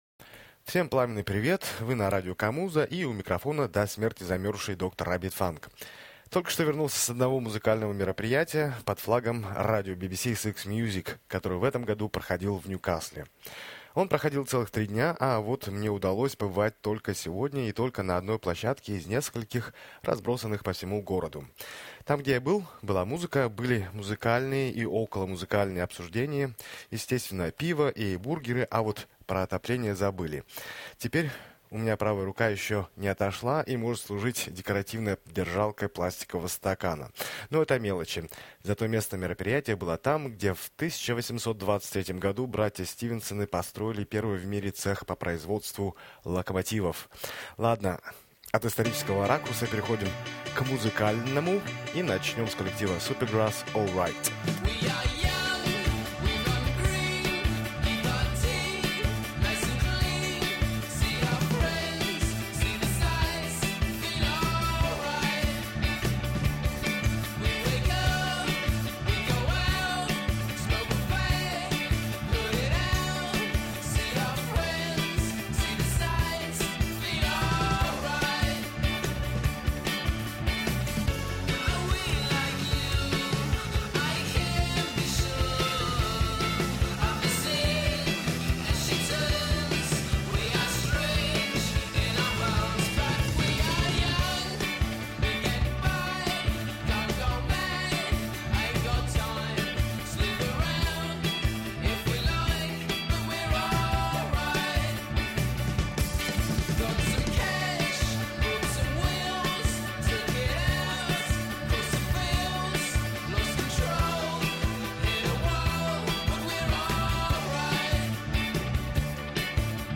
музыкальный подкаст
электронно-танцевальные треки